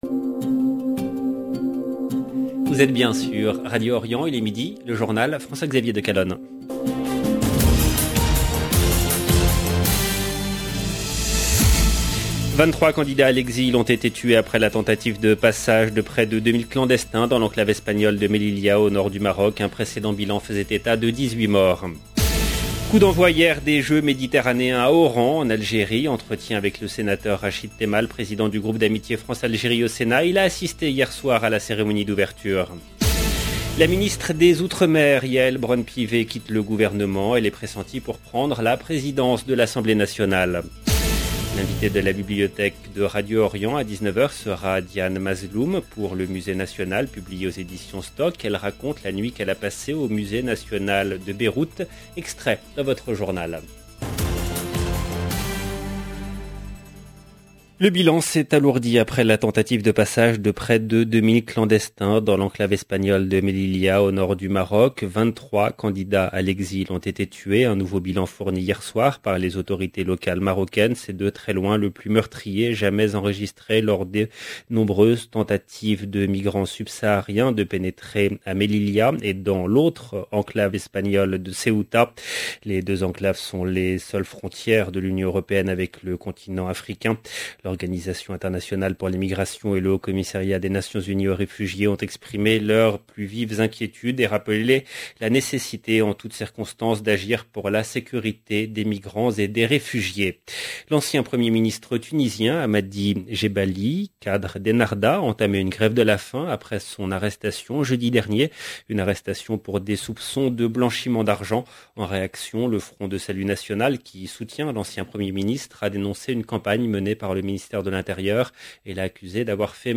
EDITION DU JOURNAL DE 12H EN LANGUE FRANCAISE DU 26/6/2022
Coup d’envoi hier des Jeux Méditerranéens à Oran, en Algérie. Entretien avec le sénateur Rachid Temal, président du groupe d’amitié France/Algérie au Sénat.